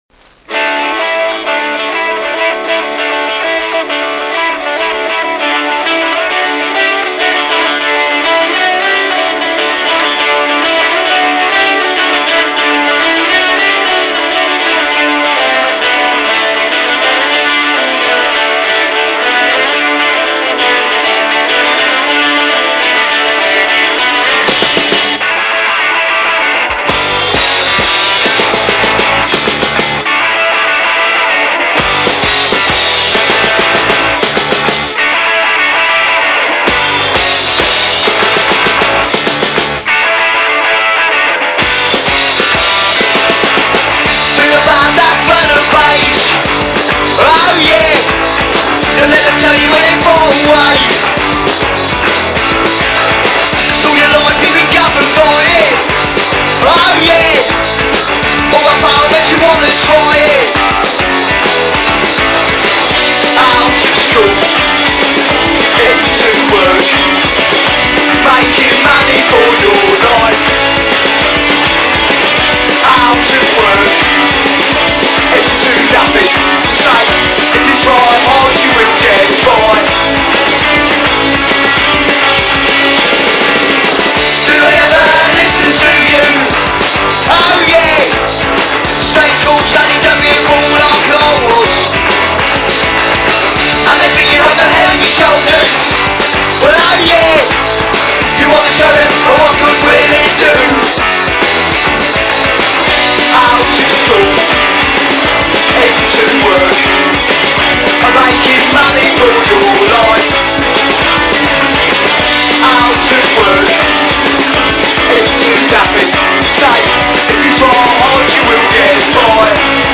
best canadian punk/power